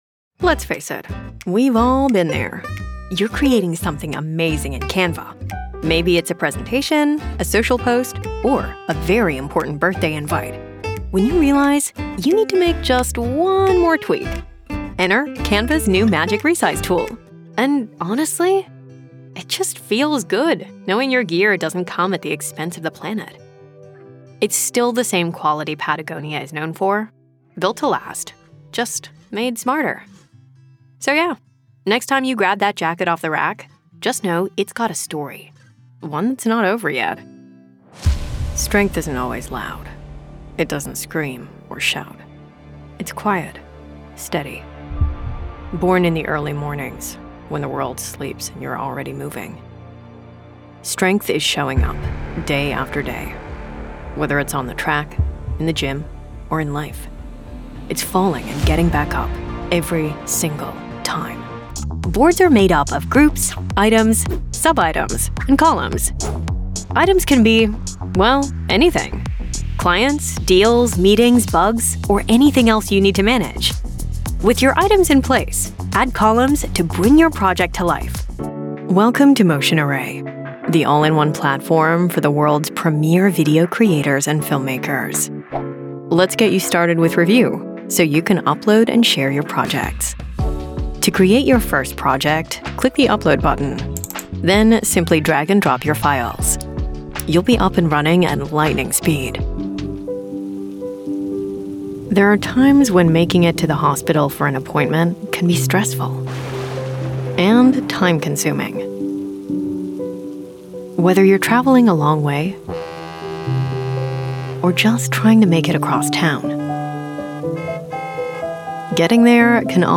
Corporate Demo - USA
Vocal qualities: Conversational, believable, friendly, warm, upbeat, cool, natural, engaging, relatable, confident, professional.